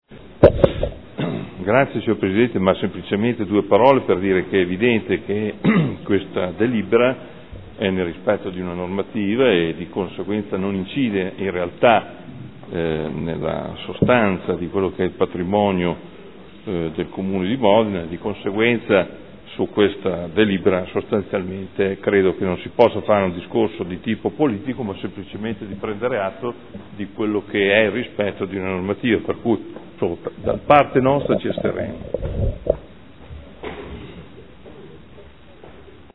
Seduta del 30/04/2015 Dichiarazione di voto. Riclassificazione dell’inventario immobiliare e ridefinizione dei valori del patrimonio immobiliare dello stato patrimoniale al 31/12/2014